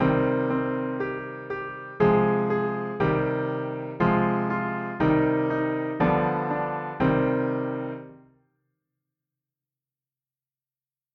And here are the chords in minor:
Twinkle, twinkle little star in C minor, with chords
“Twinkle, Twinkle Little Star” in Minor, with Chords